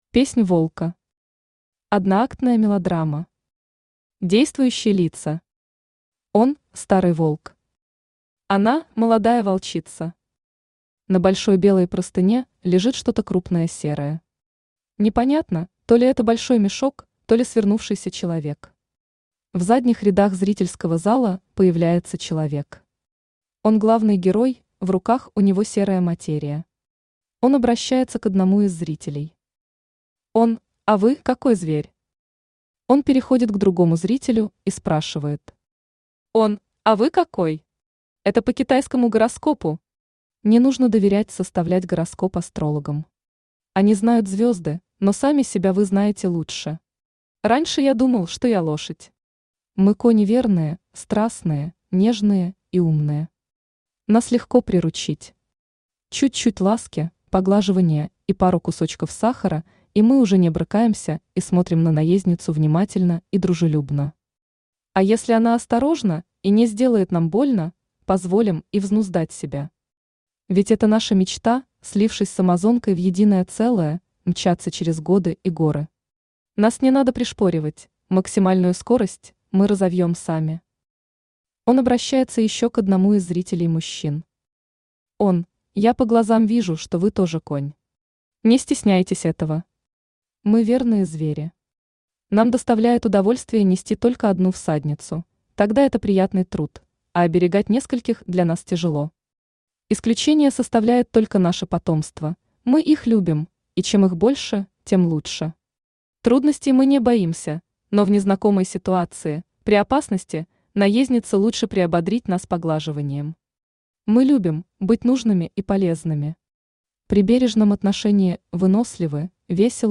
Аудиокнига Двое и любовь. Пять одноактных пьес | Библиотека аудиокниг